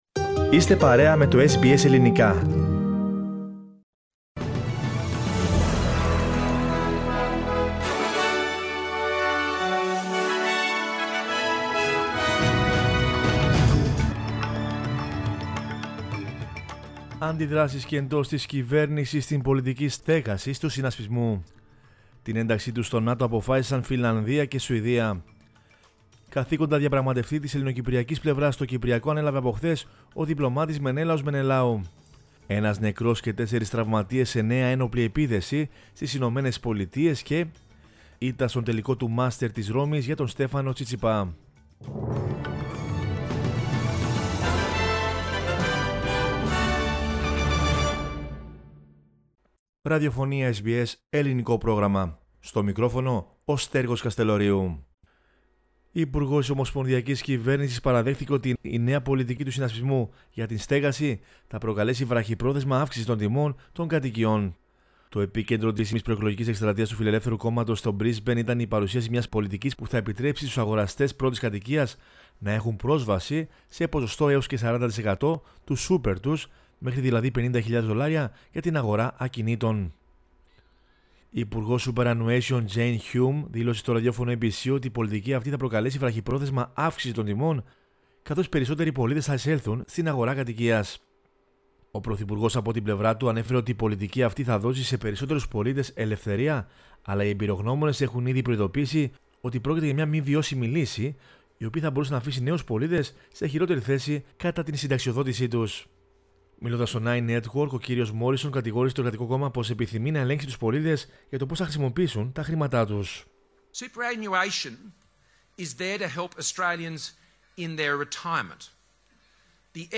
Δελτίο Ειδήσεων Δευτέρα 16.05.22
News in Greek. Source: SBS Radio